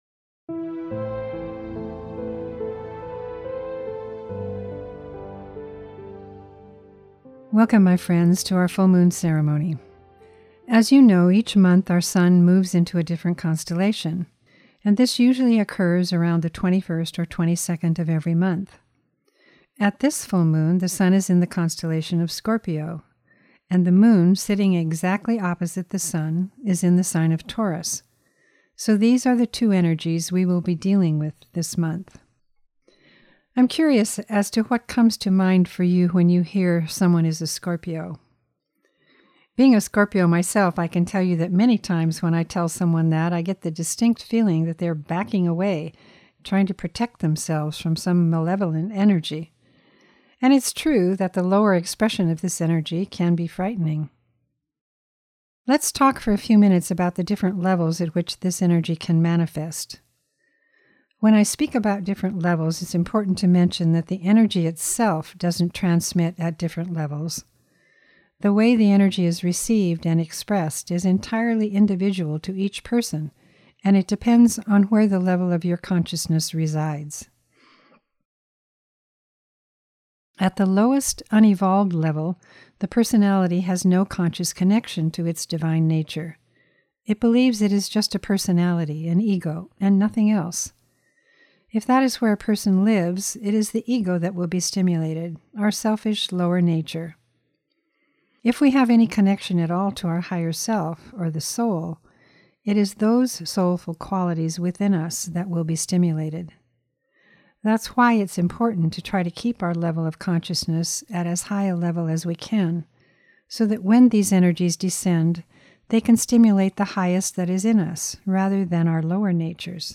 FM+Ceremony+and+Meditation+-+Scorpio.mp3